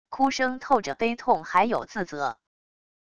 哭声透着悲痛还有自责wav音频